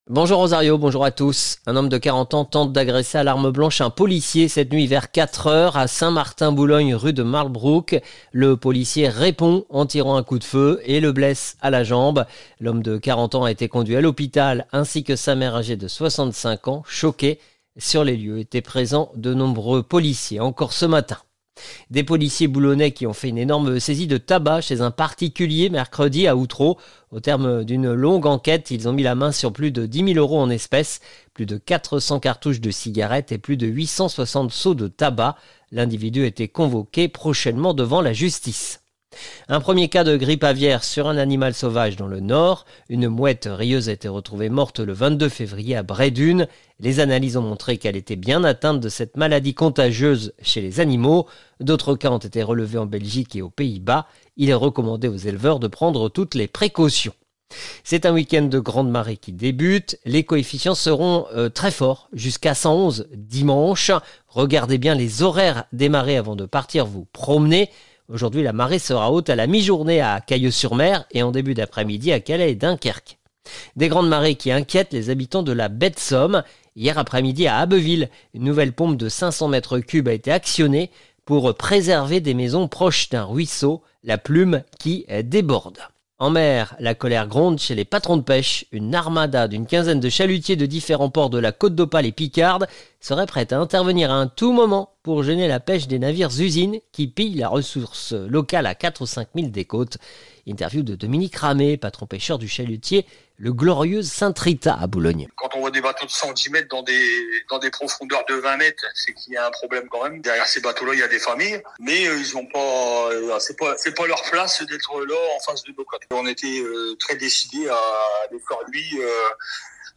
Le journal du samedi 1er mars 2025